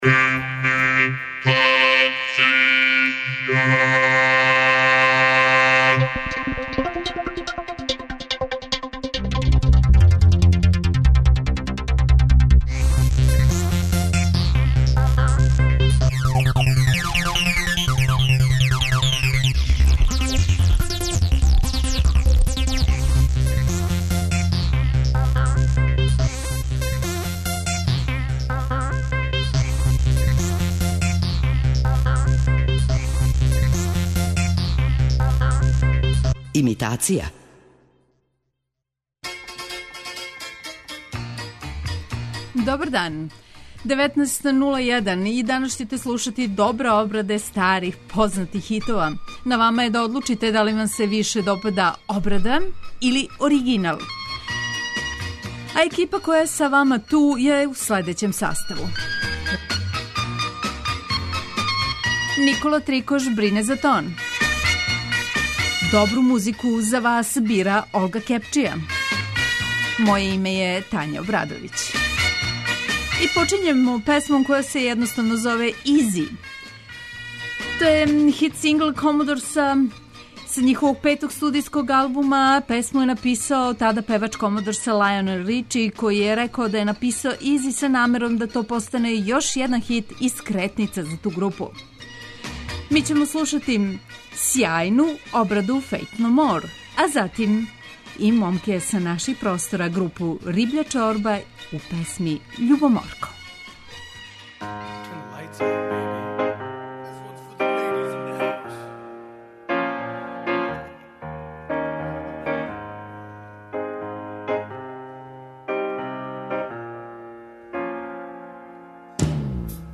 Имитација је емисија у којој се емитују обраде познатих хитова домаће и иностране музике.